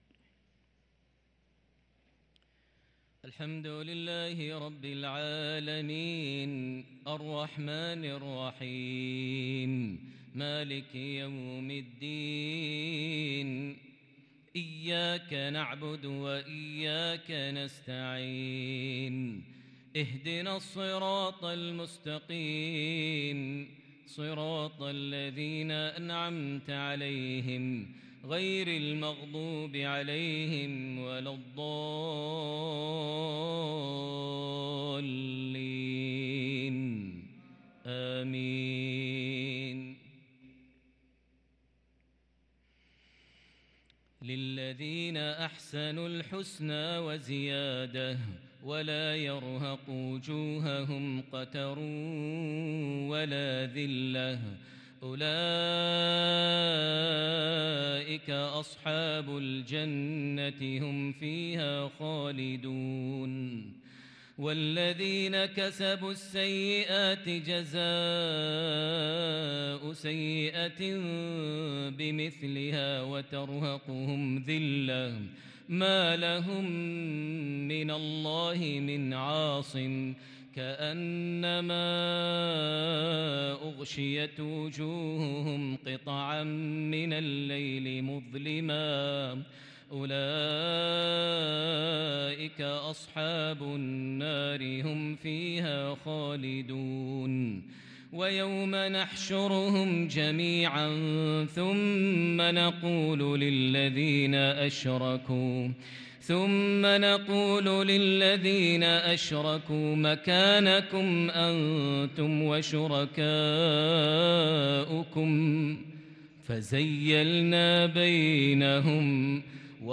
صلاة العشاء للقارئ ماهر المعيقلي 24 جمادي الأول 1444 هـ
تِلَاوَات الْحَرَمَيْن .